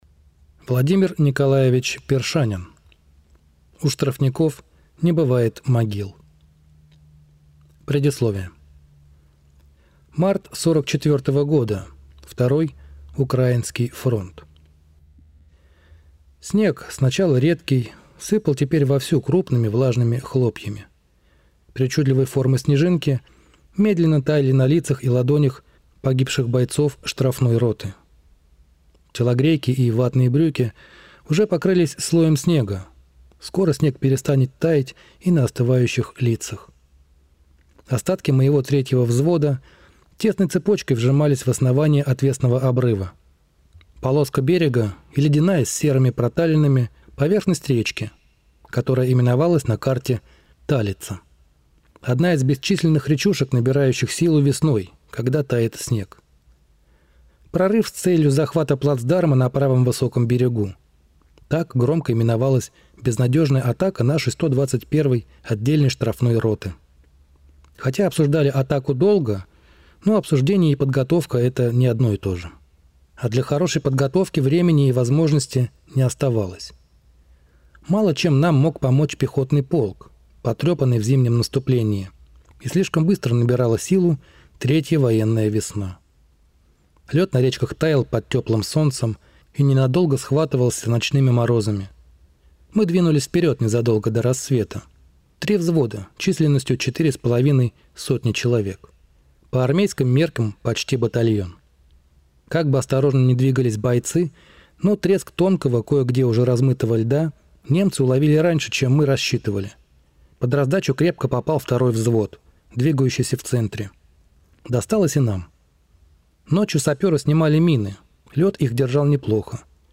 Аудиокнига У штрафников не бывает могил | Библиотека аудиокниг